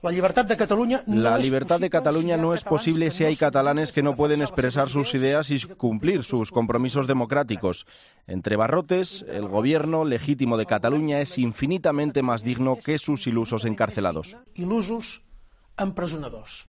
En un mensaje grabado en Bélgica y difundido por TV3, Puigdemont ha exigido la "liberación" así de los ochos miembros de su Ejecutivo sobre los que hoy se ha decretado su ingreso en prisión preventiva por la Audiencia Nacional tras la declaración unilateral de independencia.